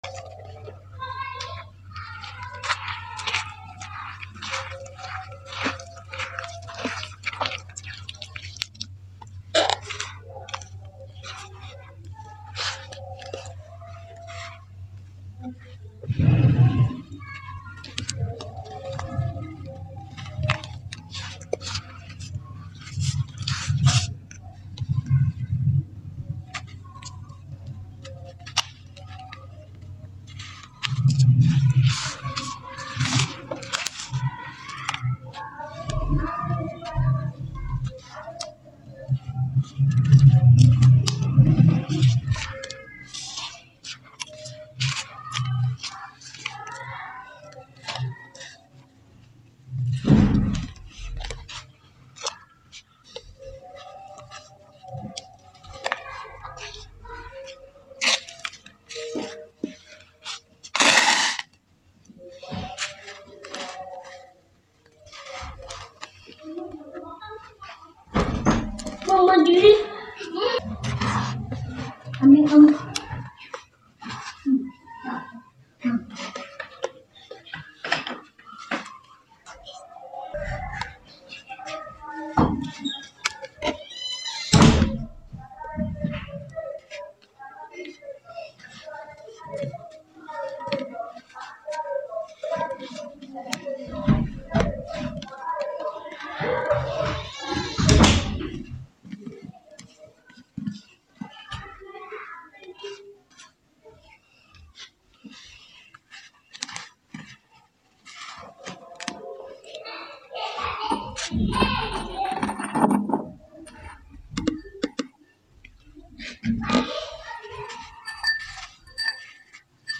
chocolate 🍫 gritty brown 🤎 sound effects free download
sand ⌛ dry floor crumbling satisfying